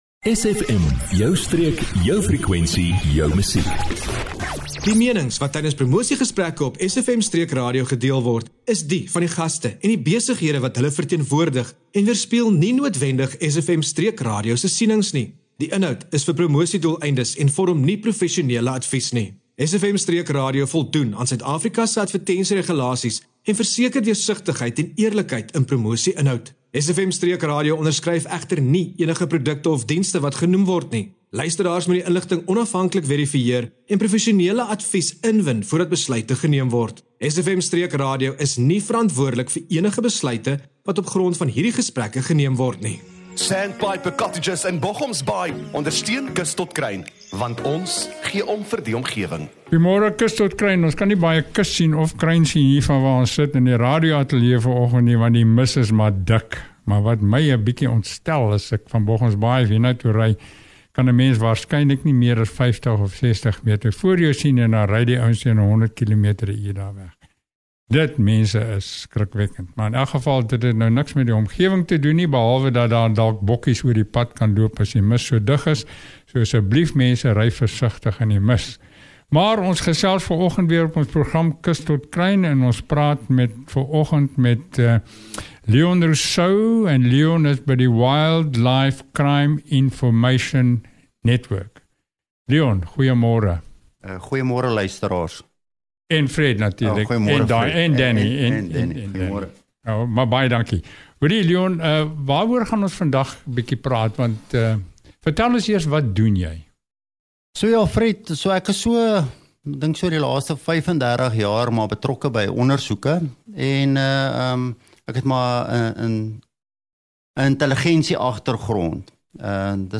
’n ontstellende maar insiggewende gesprek